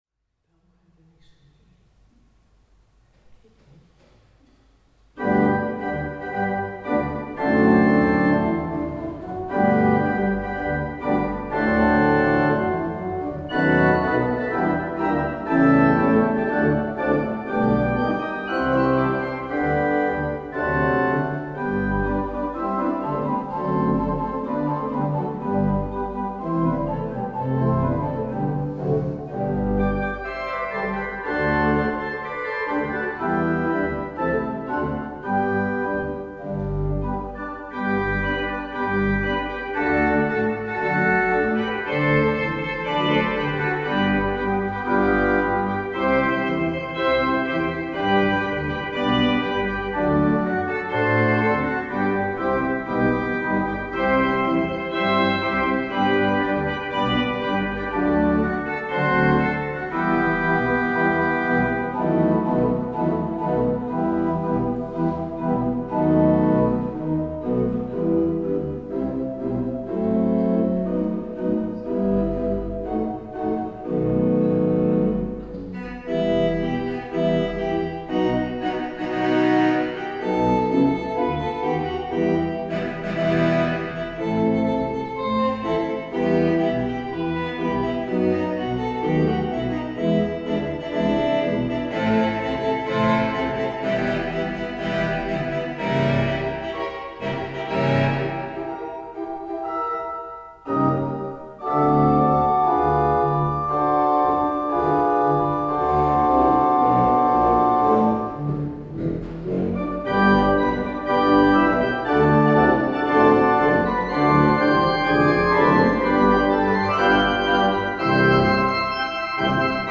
Titel 10: Improvisation über ein deutsches Volkslied „Im Frühtau zu Berge“